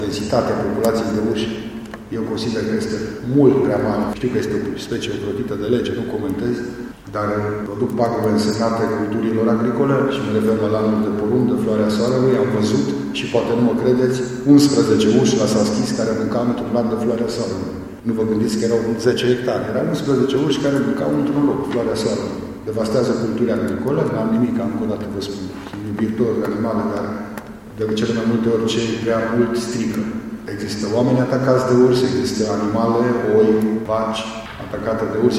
Prefectul a spus că animalele fac pagube tot mai mari în județ şi că ar trebui evaluate mai bine efectivele şi posibilităţile de împuşcare: